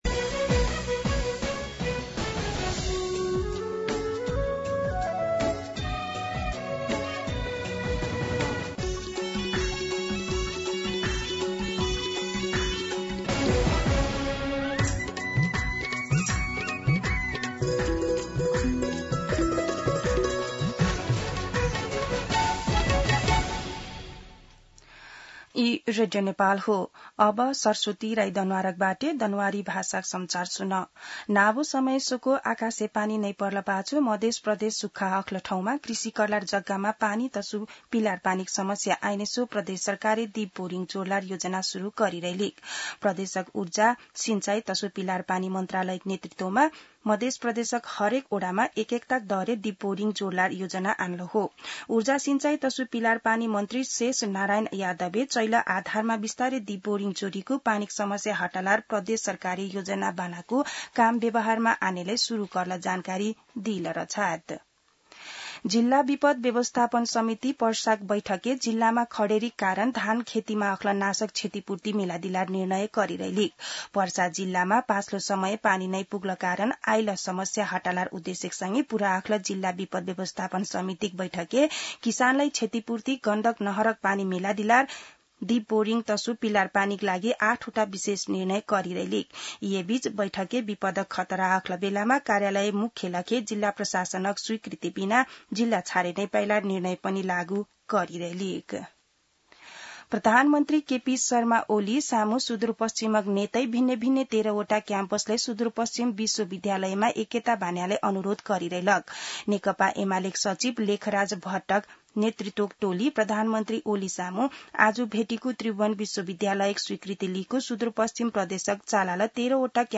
दनुवार भाषामा समाचार : ११ साउन , २०८२
Danuwar-News-11.mp3